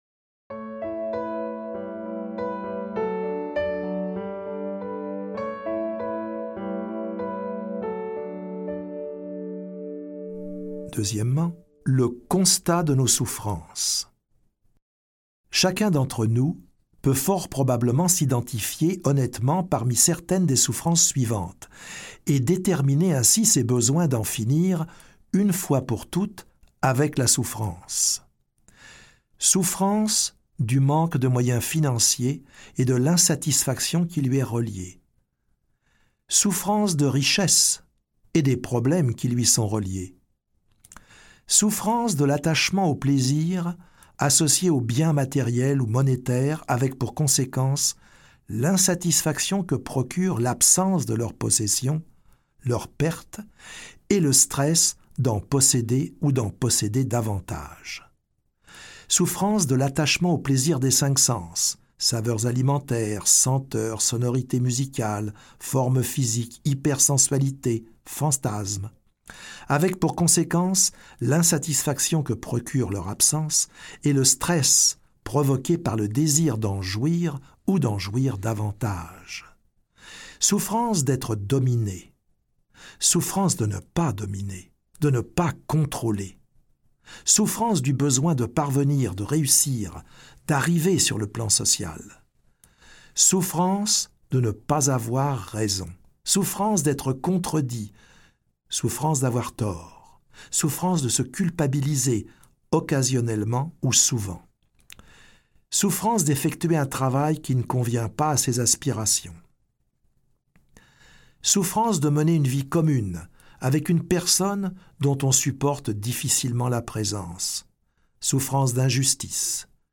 La philosophie du bien-être - Une prise de conscience incontestable de l'origine de nos malaises psychiques et corporels nous est présentée dans ce livre aud...
Extrait gratuit